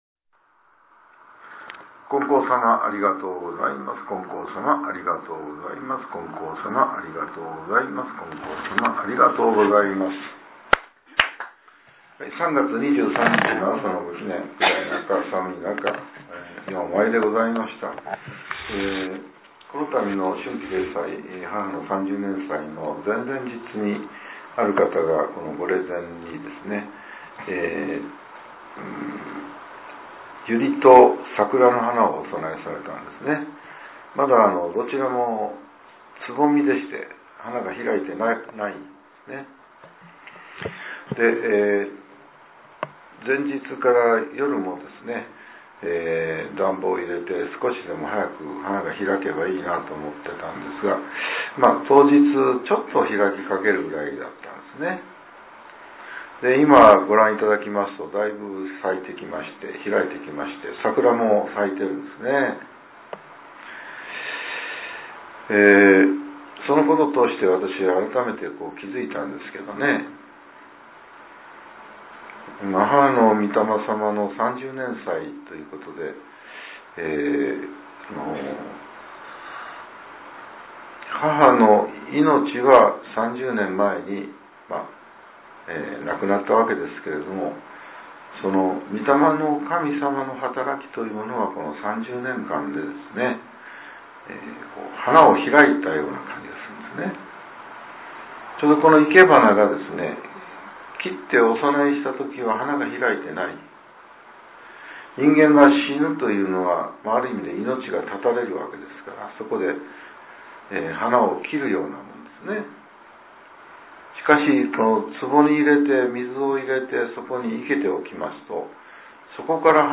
令和８年３月２３日（朝）のお話が、音声ブログとして更新させれています。